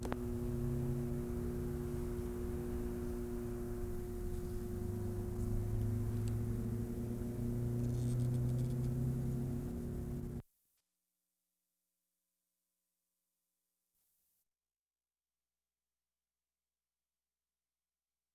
5.  POWER HUM 1'40"
5. Near Deer Lake, between Burnaby Art Gallery and the lake. Typical AC hum with few harmonics. Freeway traffic in distance.